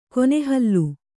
♪ kone hallu